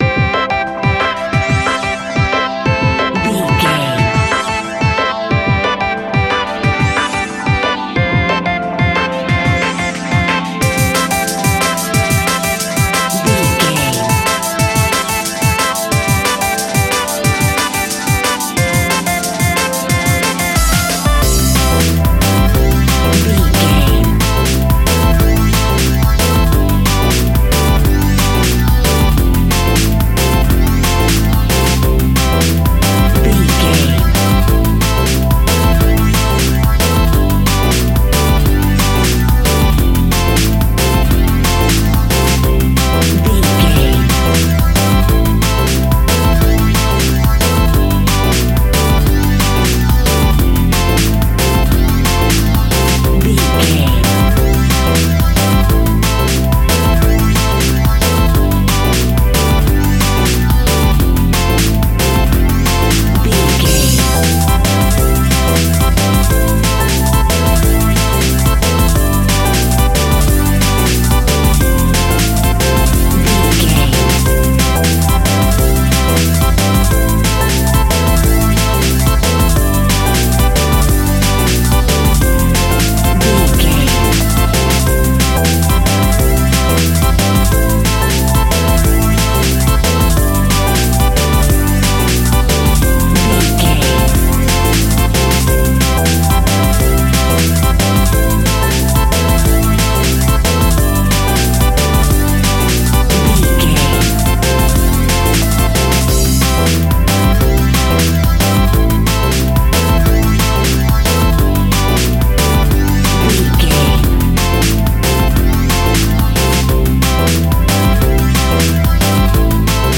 Ionian/Major
Fast
groovy
uplifting
futuristic
driving
energetic
repetitive
bouncy
synthesiser
drum machine
electric piano
Drum and bass
electronic
instrumentals
synth bass
synth lead
synth pad